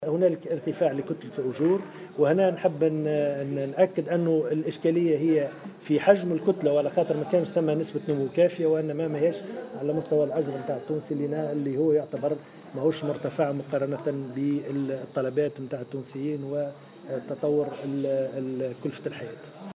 أكد وزير المالية محمد رضا شلغوم في تصريح صحفي اليوم الثلاثاء أن الأجور غير مرتفعة في تونس مقارنة مع كلفة المعيشة.